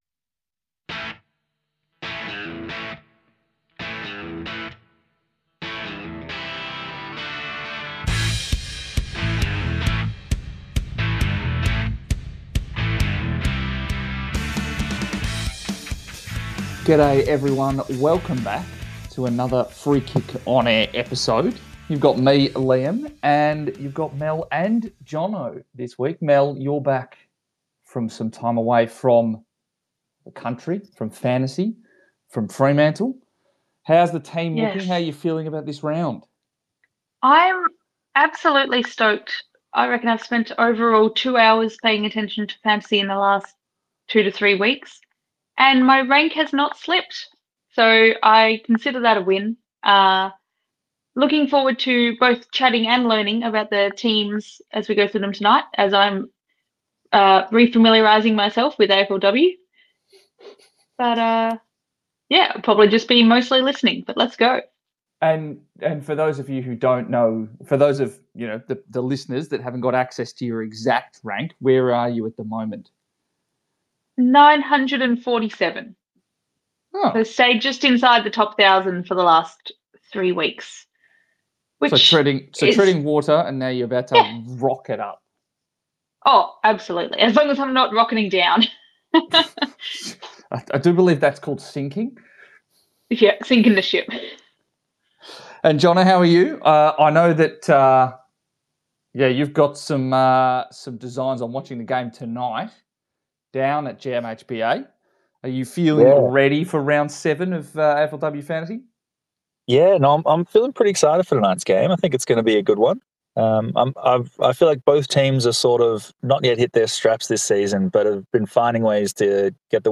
A few passionate AFLW fans chatting all things fantasy!